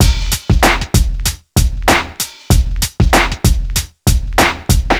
Free drum groove - kick tuned to the F note. Loudest frequency: 1998Hz
• 96 Bpm Drum Groove F Key.wav
96-bpm-drum-groove-f-key-smV.wav